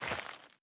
added stepping sounds
DirtRoad_Mono_02.wav